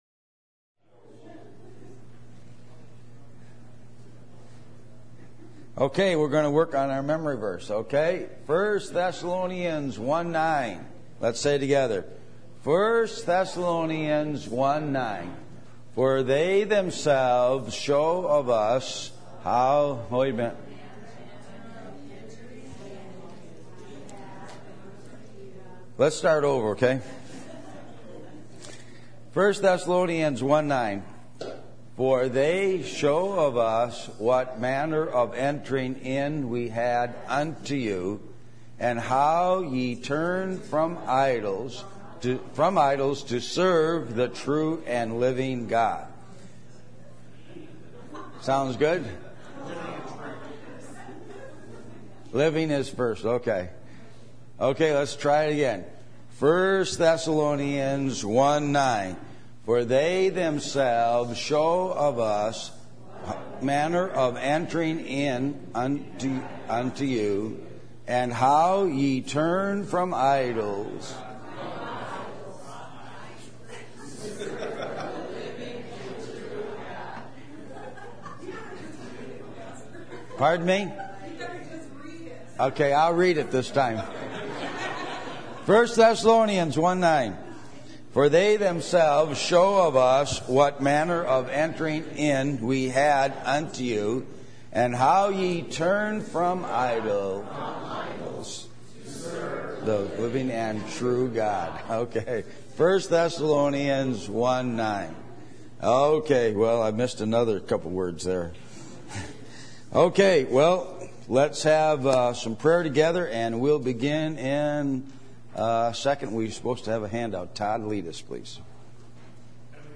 Adult Sunday School %todo_render% « Come Outside The Camp